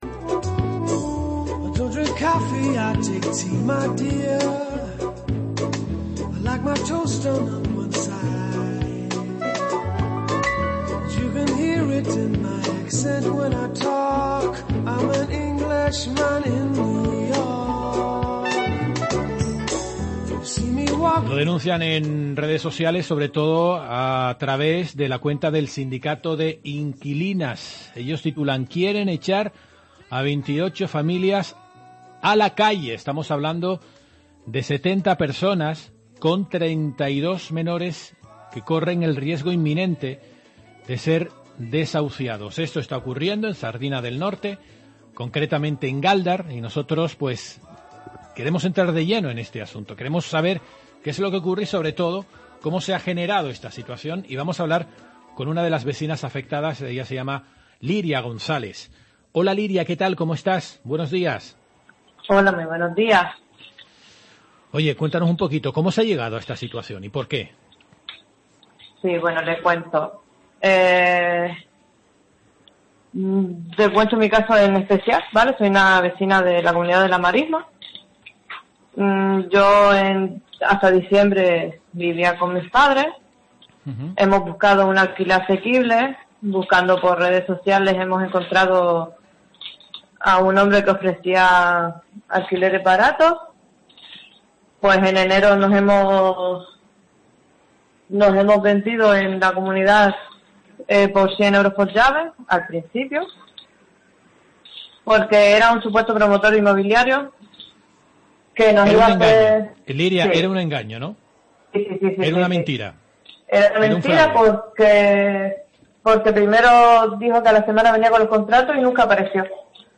“Nosotros no queremos estar aquí gratis ni mucho menos, si nos ponen un alquiler social o algo que podamos asumir lo pagaremos”, ha señalado en los micrófonos de COPE Gran Canaria.